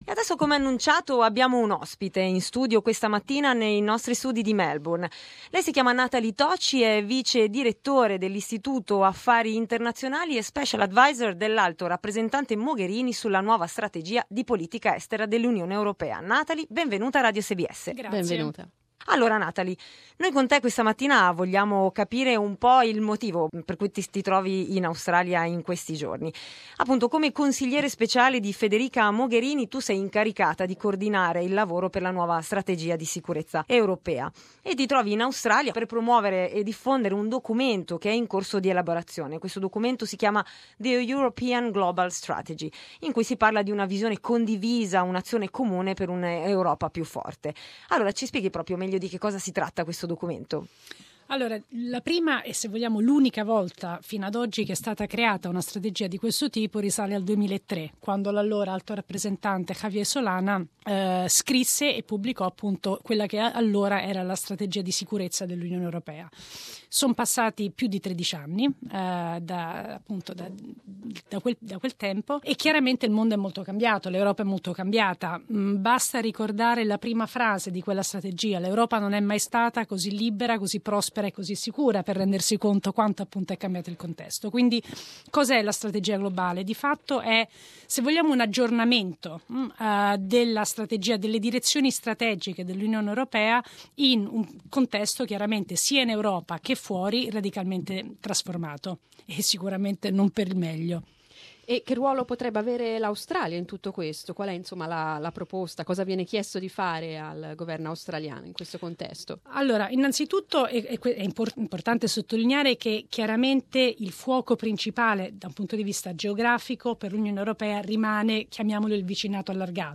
Nathalie Tocci ci spiega i dettagli.